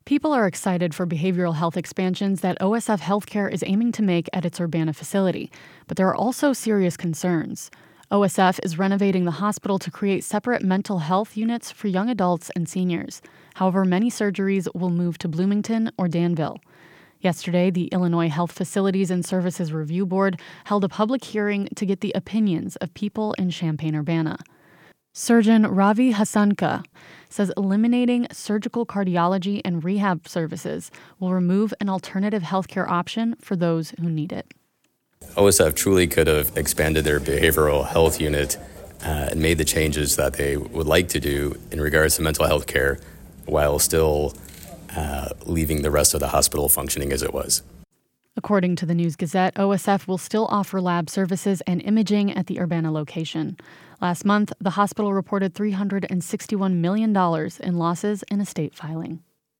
The Illinois Health Facilities and Services Review Board held a public hearing Thursday as part of a required process to consider OSF’s proposal.